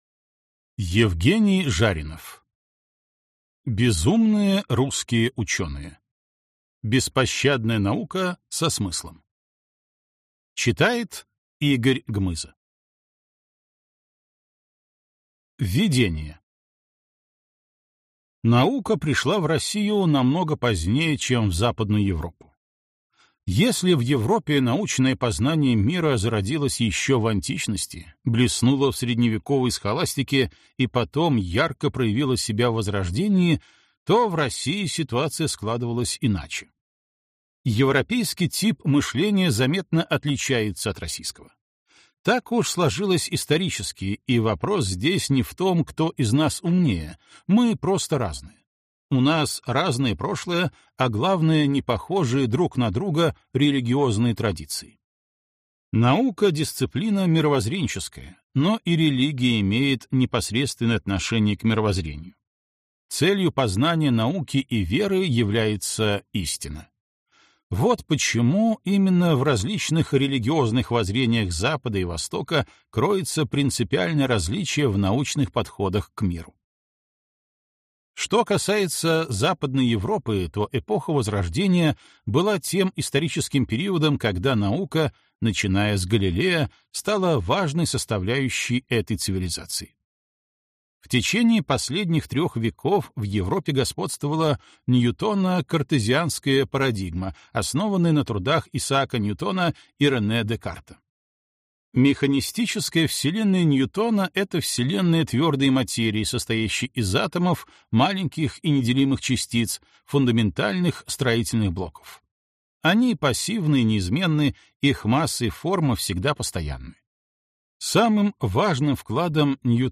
Аудиокнига Безумные русские ученые. Беспощадная наука со смыслом | Библиотека аудиокниг